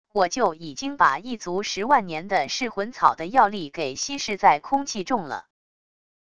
我就已经把一族十万年的噬魂草的药力给稀释在空气中了wav音频生成系统WAV Audio Player